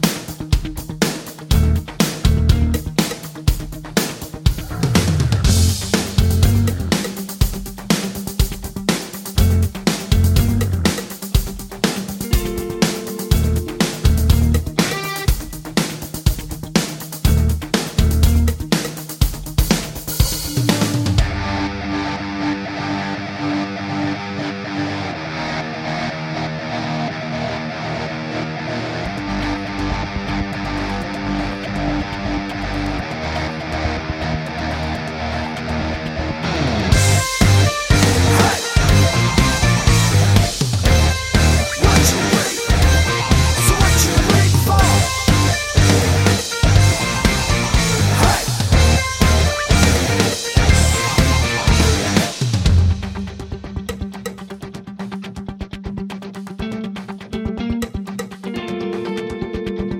Explicit Version Rock 3:23 Buy £1.50